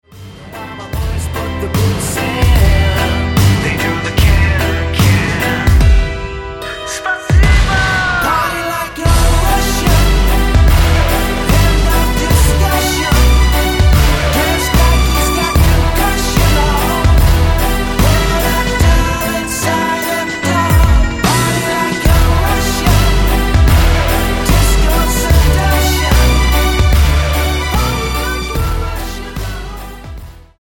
--> MP3 Demo abspielen...
Tonart:E mit Chor